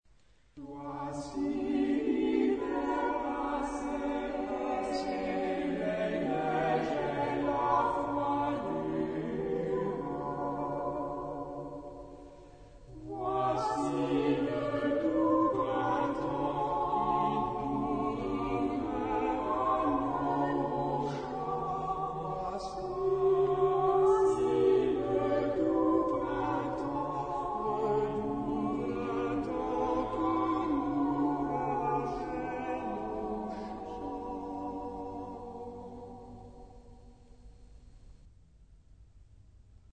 Genre-Style-Forme : Populaire ; Profane
Caractère de la pièce : modéré
Type de choeur : SATB  (4 voix mixtes )
Tonalité : sol majeur
Origine : Angoumois (France)